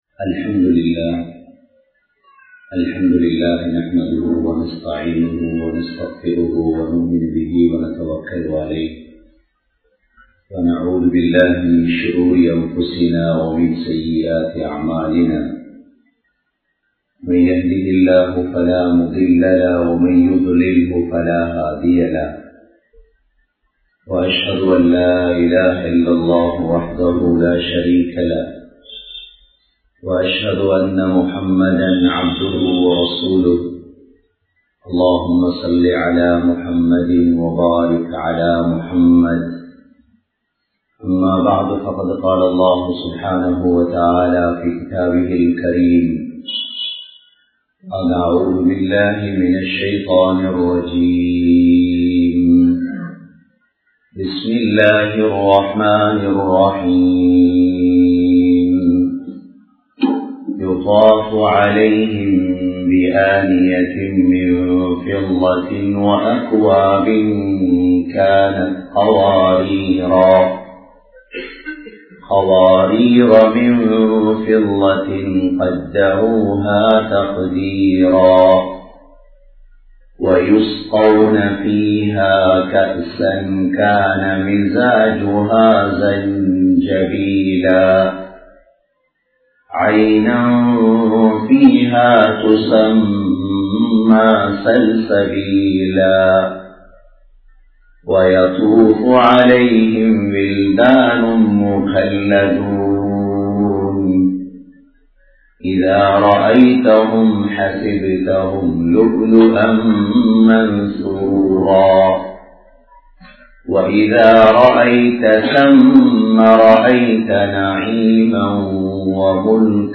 Oru Oorin Munnetrathitkaana Vali(ஒரு ஊரின் முன்னேற்றத்திற்கான வழி) | Audio Bayans | All Ceylon Muslim Youth Community | Addalaichenai